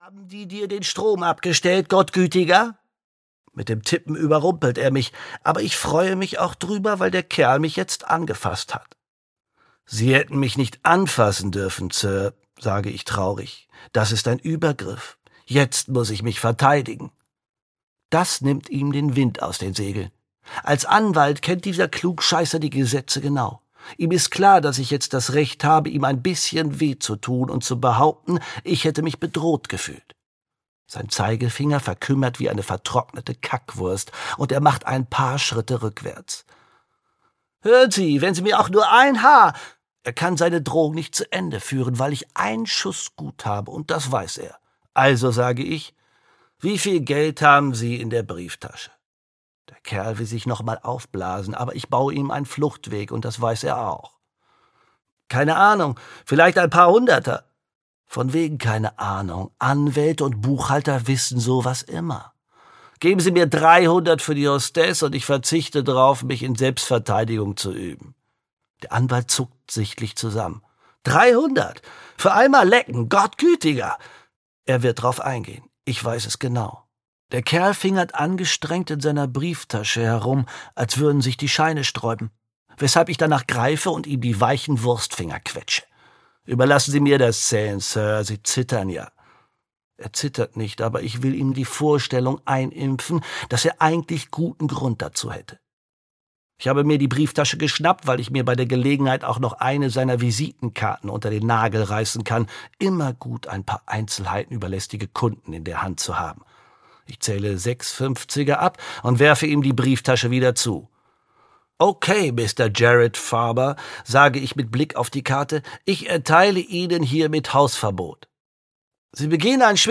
Hörbuch Der Tod ist ein bleibender Schaden, Eoin Colfer.